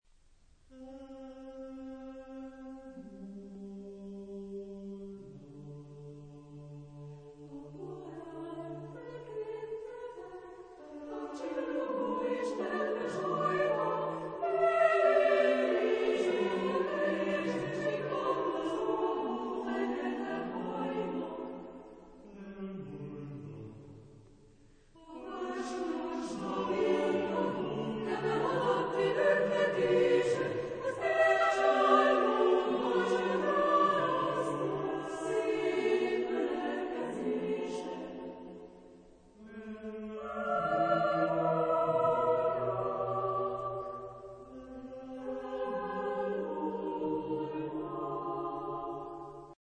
Género/Estilo/Forma: Lírica ; Coro ; ciclo
Tipo de formación coral: SATB  (4 voces Coro mixto )
Tonalidad : tonal